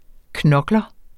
Udtale [ ˈknʌglʌ ]